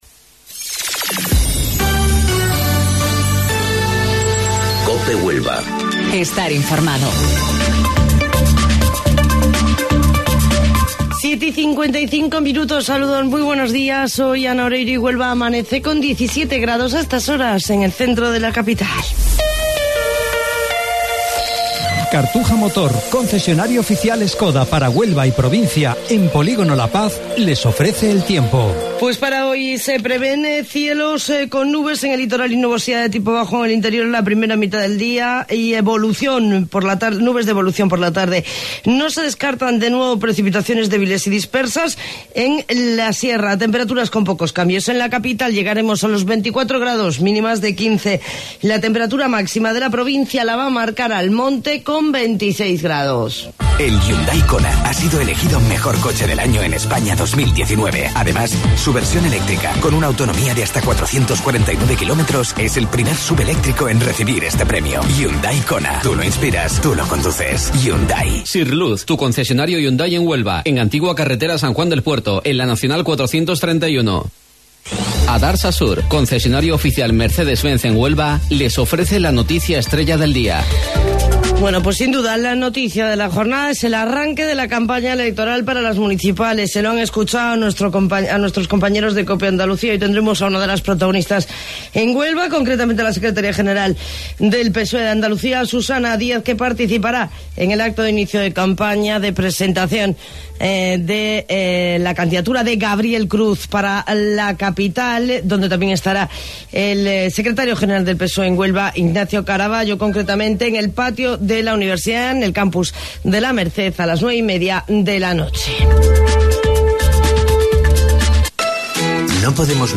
AUDIO: Informativo Local 07:55 del 9 de Mayo